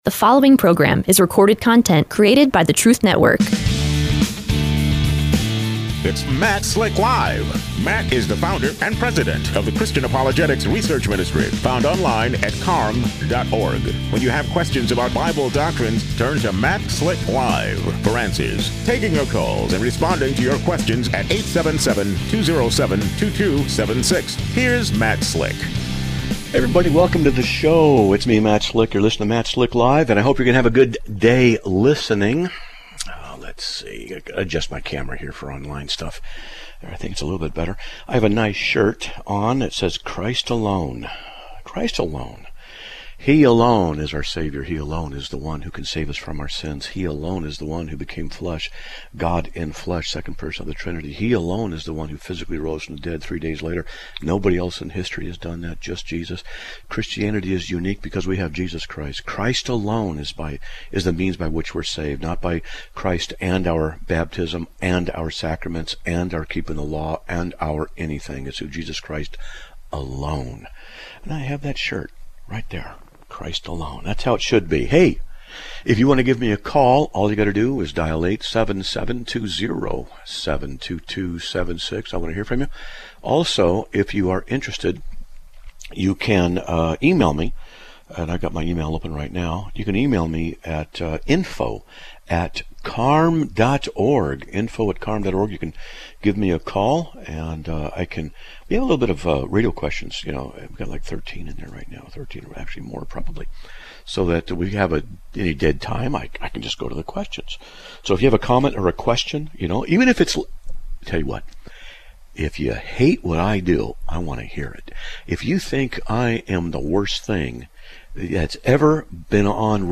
The show airs live on the Truth Network, Monday through Friday, 6-7 PM, EST (3-4 PM, PST) You can also watch a live stream during the live show on RUMBLE!
MSL: March, 16 2023 CARM This show LIVE STREAMS on RUMBLE during the Radio Broadcast!